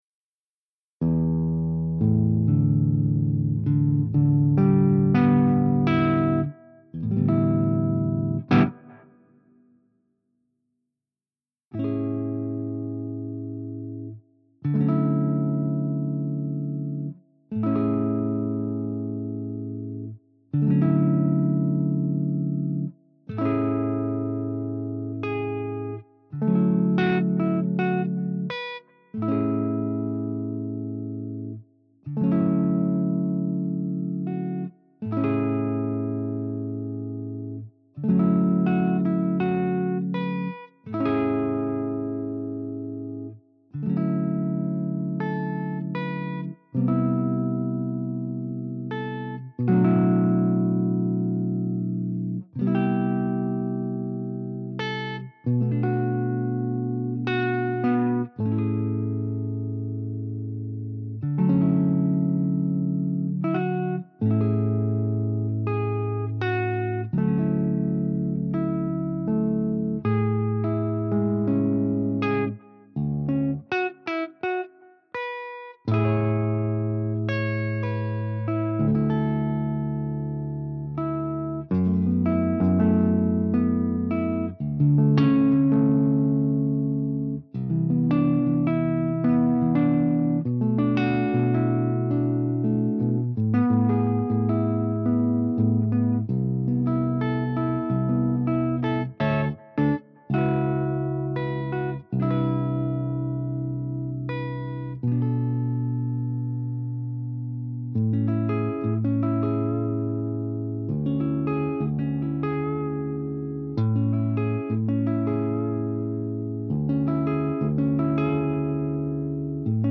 a mello "lofi guitar" rendition